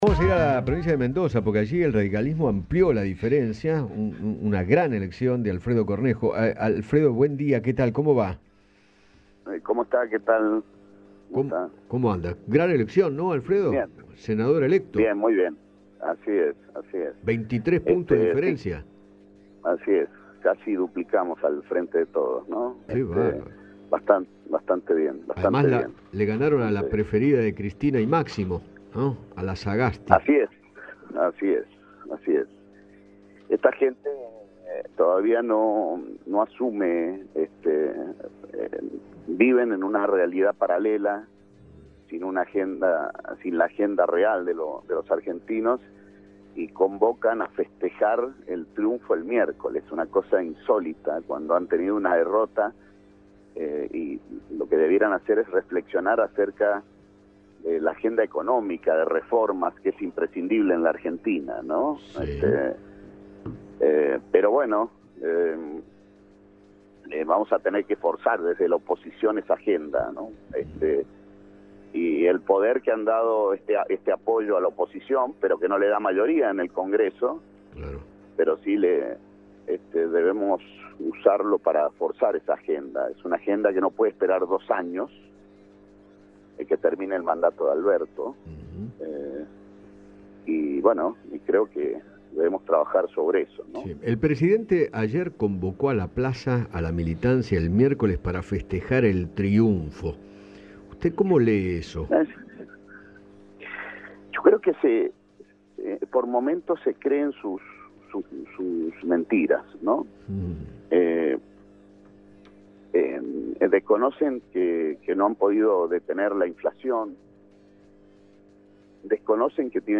Alfredo Cornejo, presidente de la UCR, conversó con Eduardo Feinmann sobre la derrota del Frente de Todos en las elecciones legislativas y sostuvo que “convocan a festejar pero deberían reflexionar”.